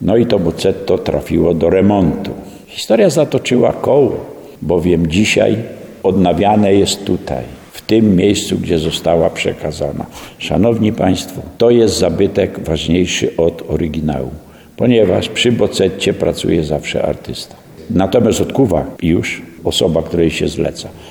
mówił historyk